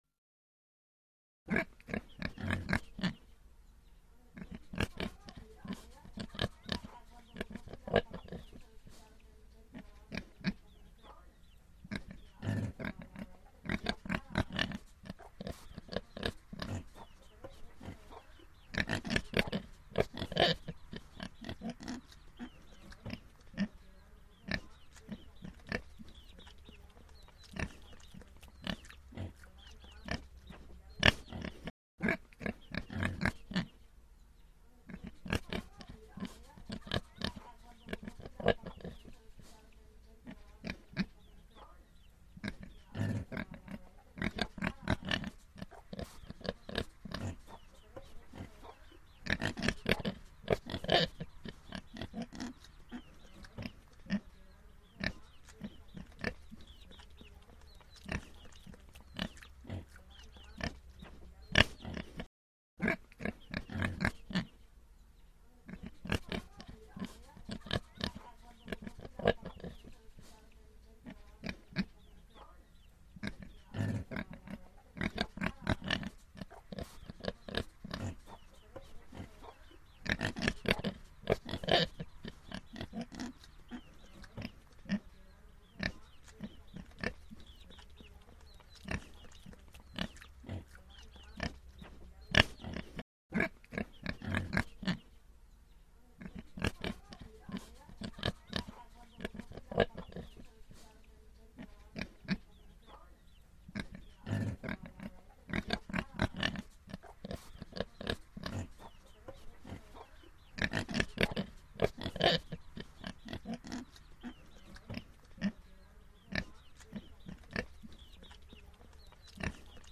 3D spatial surround sound "Pig grunting"
3D Spatial Sounds